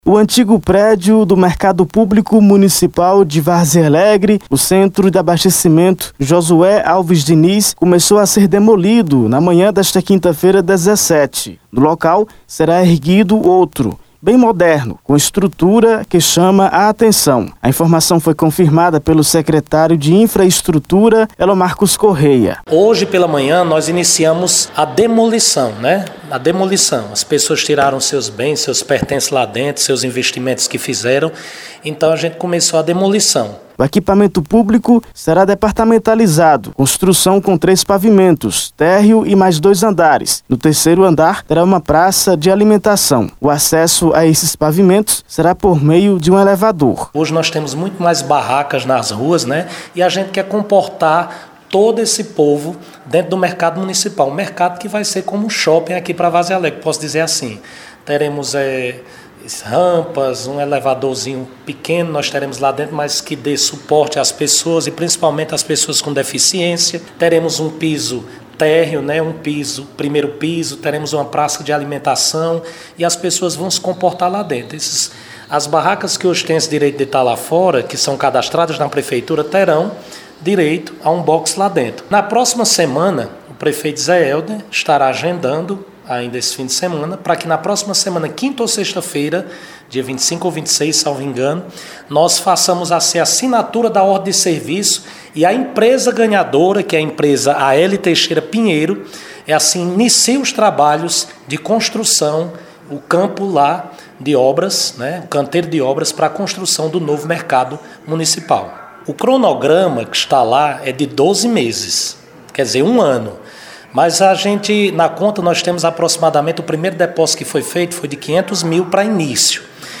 Confira detalhes na reportagem de áudio: